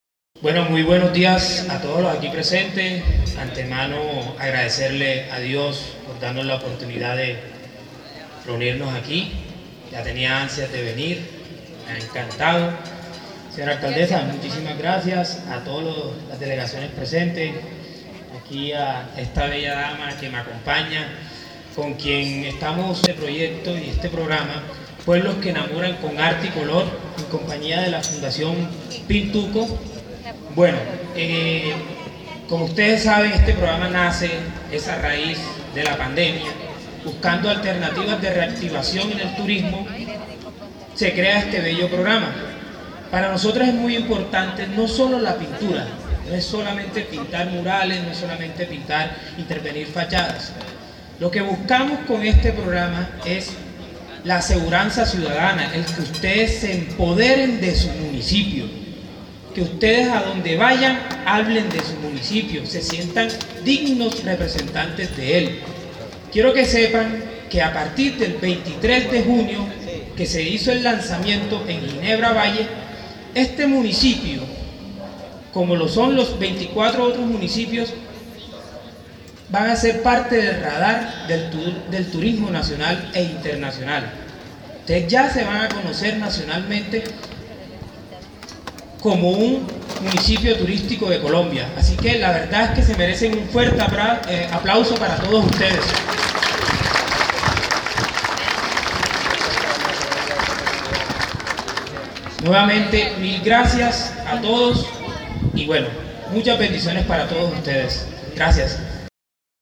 En el parque de la vereda Ingenio Centro este martes en la mañana se realizó el lanzamiento del programa “Sandoná pueblo que enamora, con arte pintura y color”.